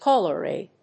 アクセントcáller Í[N16-A154]